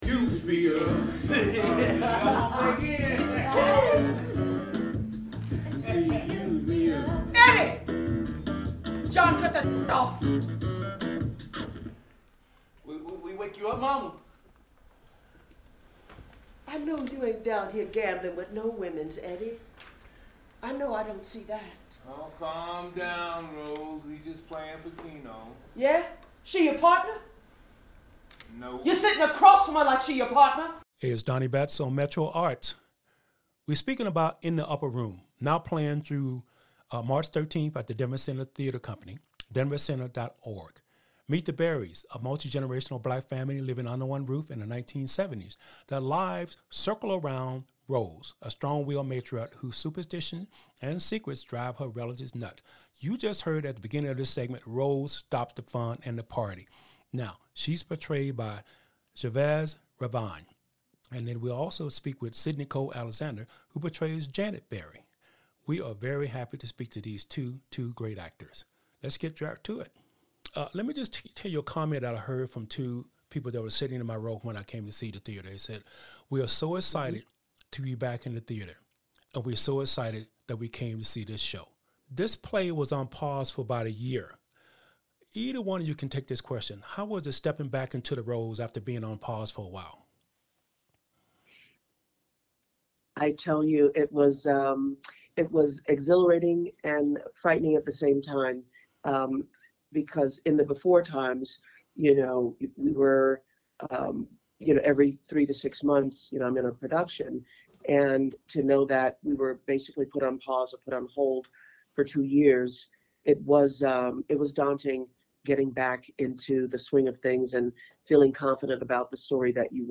In-The-Upper-Room-Interview-for-Metro-Arts.mp3